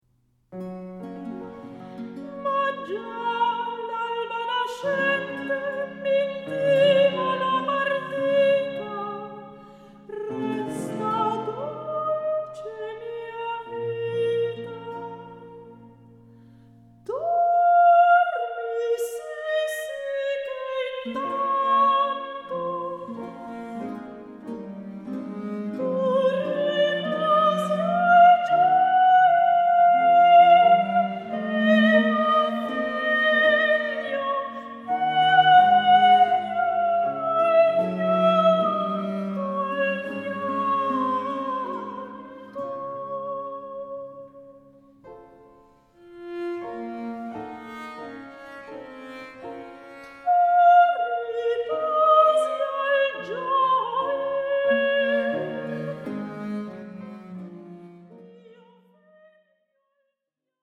for soprano and basso continuo